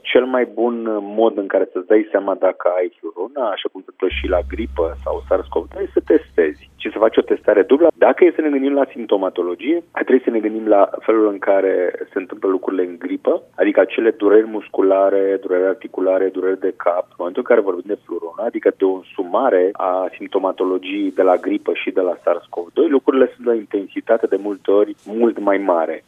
a explicat, la Radio România Actualităţi, care sunt principalele simptome care ar trebui să îi facă de bolnavi să meargă la medicul de familie: